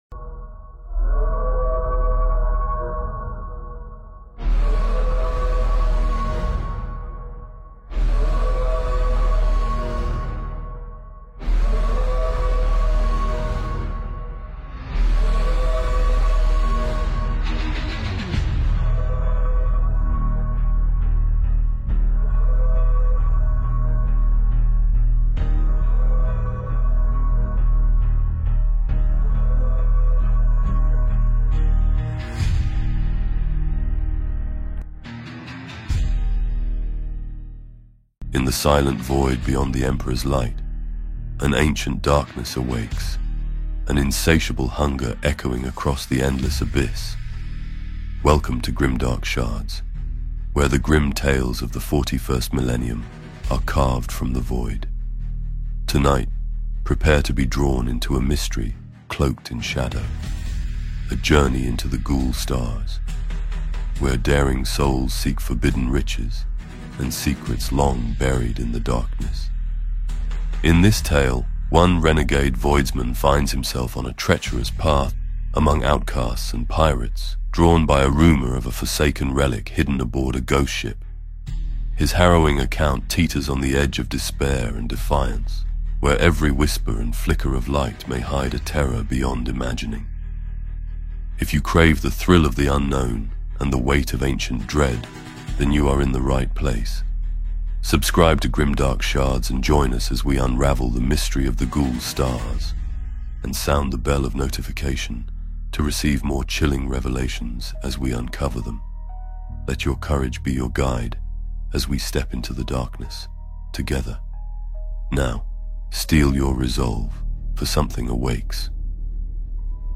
Step into the void beyond the Emperor’s light with "The Ghoul Stars Hunger," an original Warhammer 40K audiobook that plunges you into a realm of ancient dread and relentless horror.